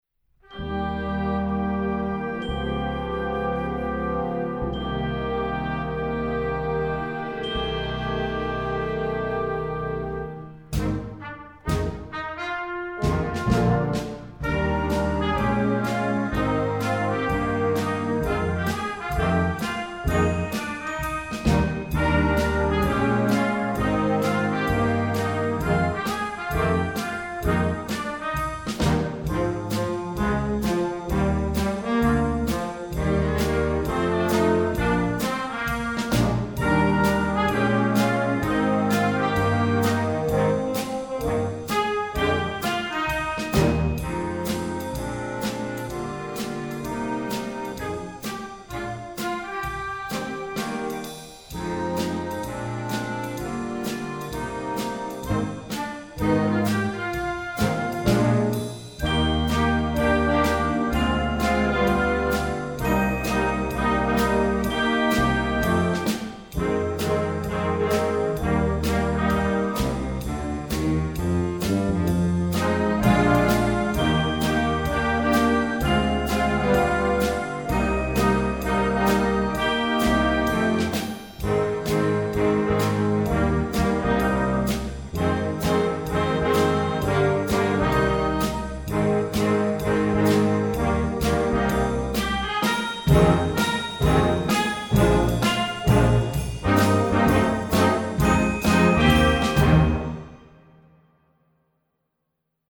Weihnachtsmusik für Jugendblasorchester
Besetzung: Blasorchester